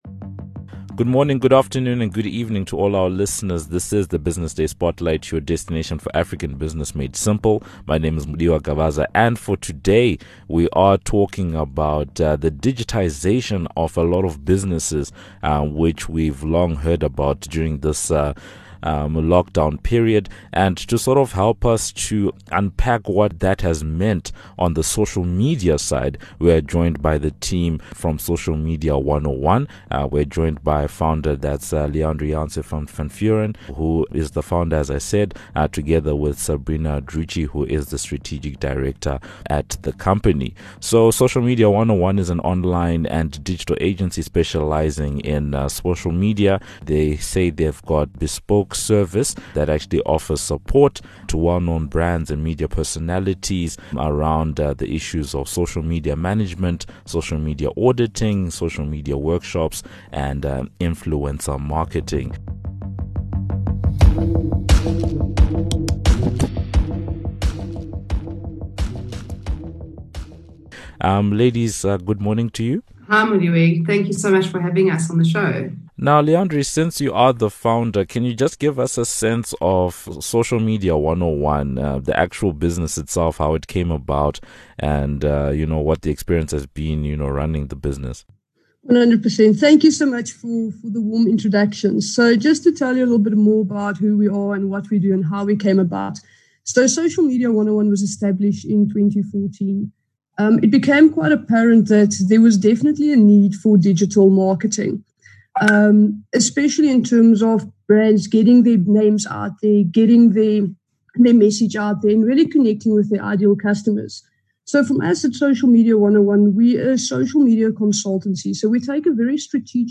The conversation touches on the evolution of social media use in business during the pandemic, how much businesses should be spending on social media for advertising and an outlook for the sector.